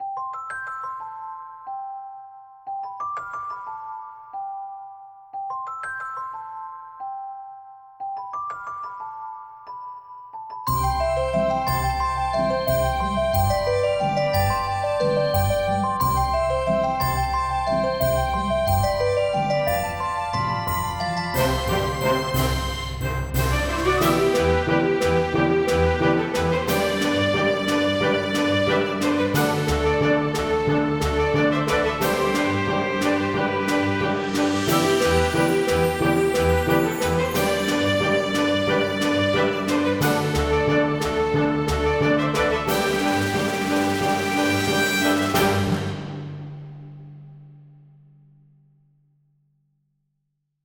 Léger, sautillant, dansant, parfaitement maitrisé...
Le glockenspiel est évidemment tout à fait adapté, mais tu ne t'en es pas contenté, l'orchestre intervient, sans que la légèreté du morceau en pâtisse...